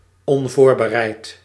Ääntäminen
IPA: /kalt/